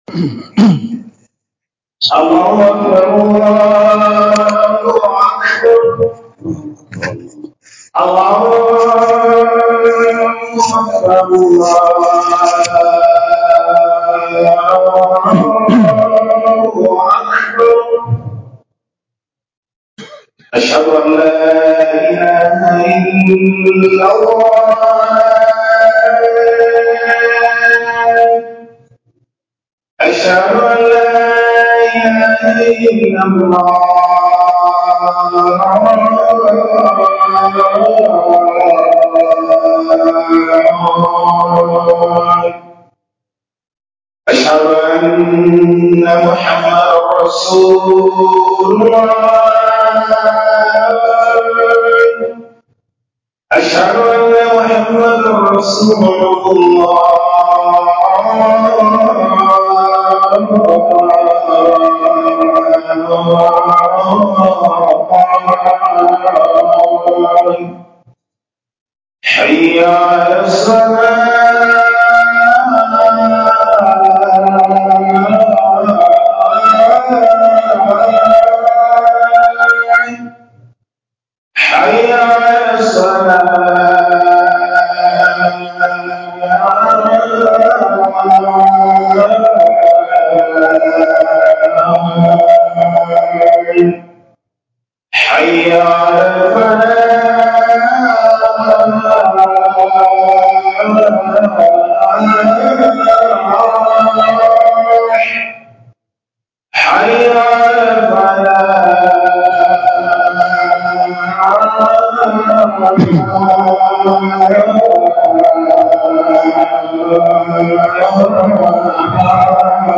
Hudubar Juma'a 3 janv._ 2025